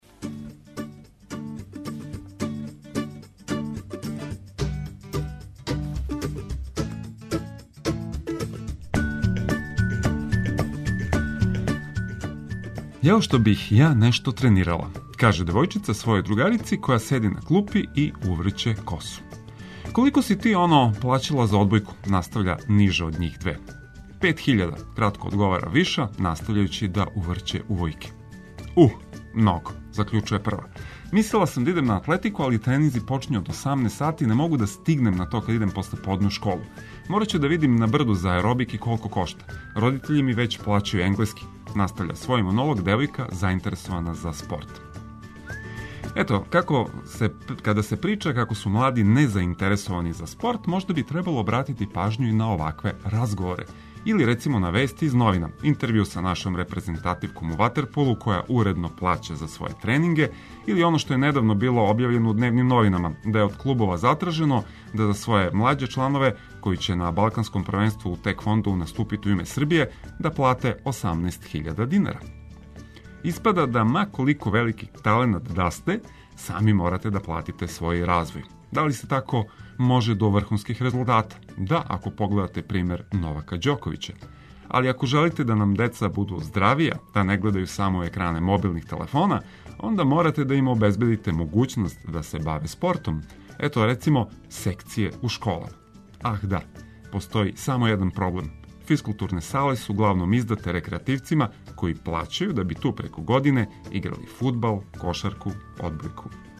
Буђење уз прецизне информације и савршену музику за расањивање.